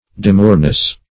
Demureness \De*mure"ness\ (d[-e]*m[=u]r"n[e^]s), n.